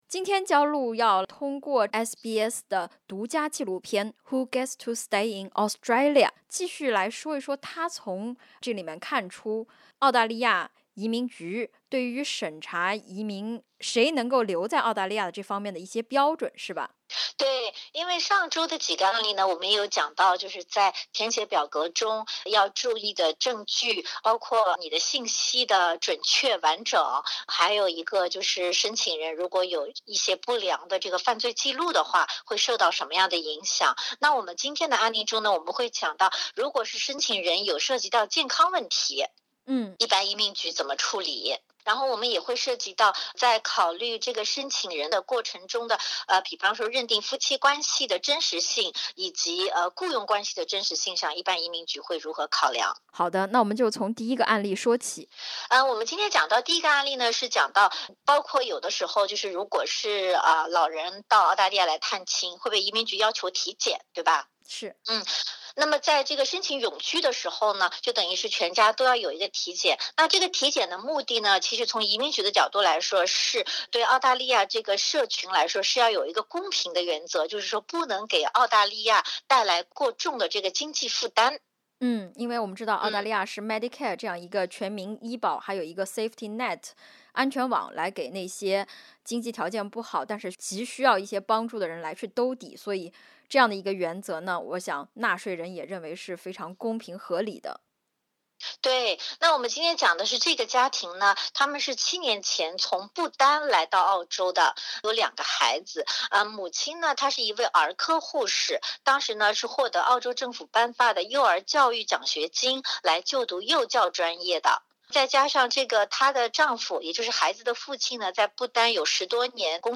点击封面图片收听采访。 根据SBS独家播出的一档纪录片《Who Gets To Stay In Australia?》，每三分钟就有一个人获得澳大利亚的永久居留权，但是每年也有超过4万人的签证申请遭拒。